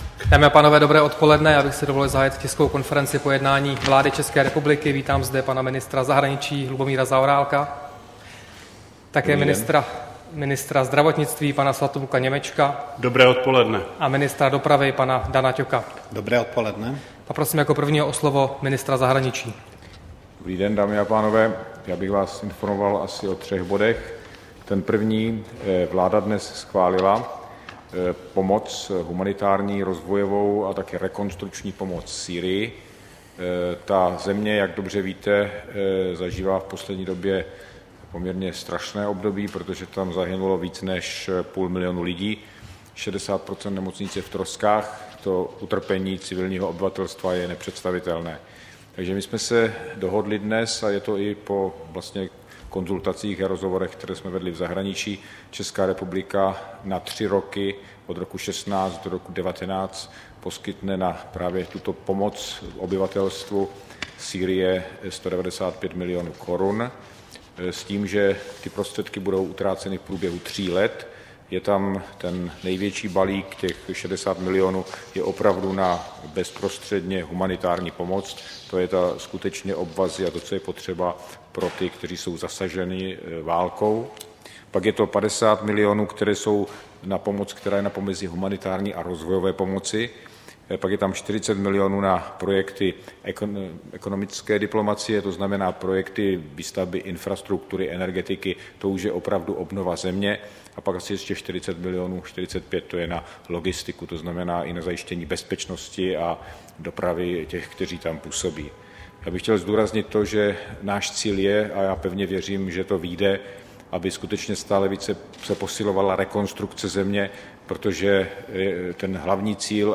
Tisková konference po jednání vlády 27. června 2016